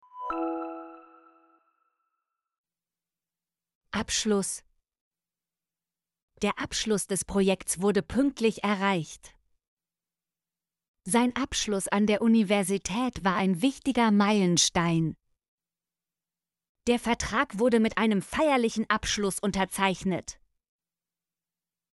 abschluss - Example Sentences & Pronunciation, German Frequency List